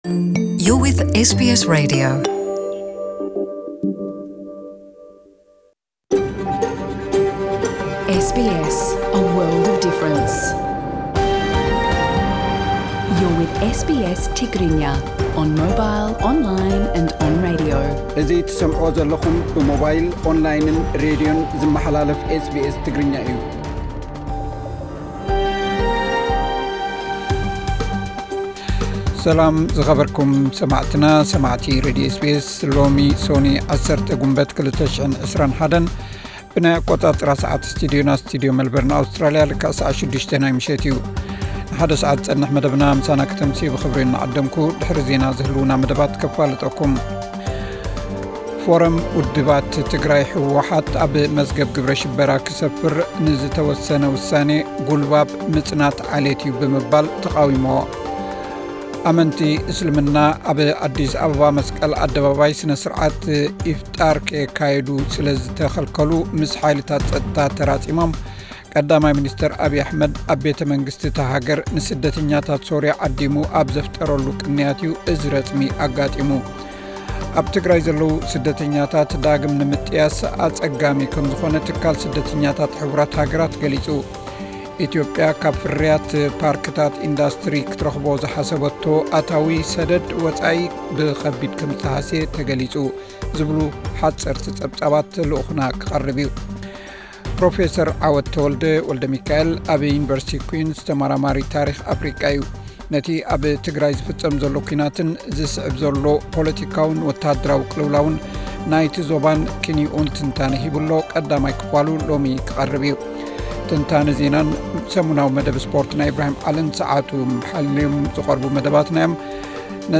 ዕለታዊ ዜና 10 ግንቦት 2021 SBS ትግርኛ